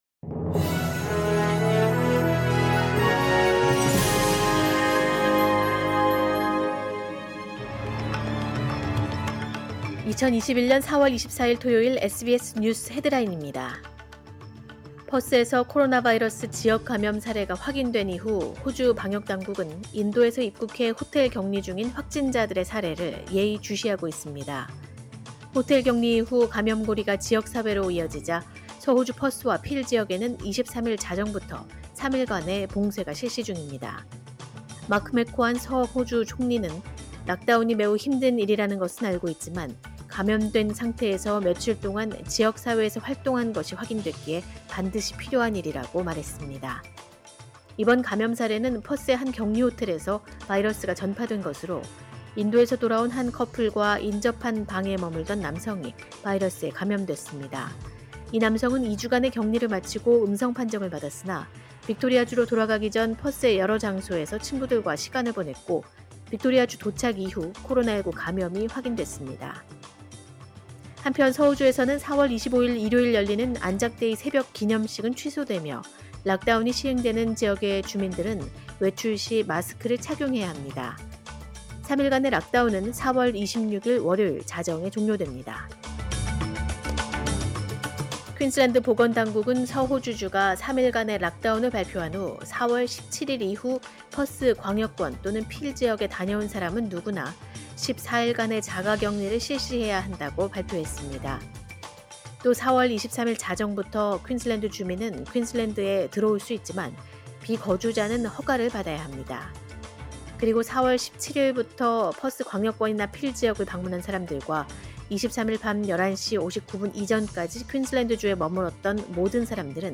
2021년 4월 24일 토요일 SBS 뉴스 헤드라인입니다.